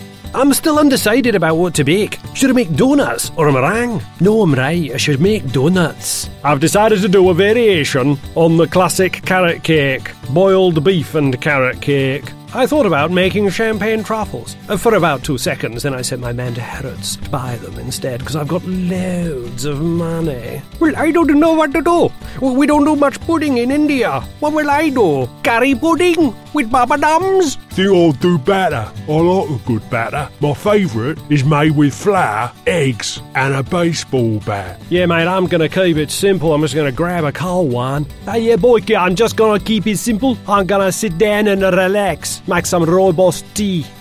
Conversational (all the rage these days) professional, classy(think Downton abbey!) and full of character (A whole village full)
schottisch
britisch
Sprechprobe: Sonstiges (Muttersprache):